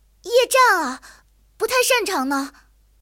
野牛夜战语音.OGG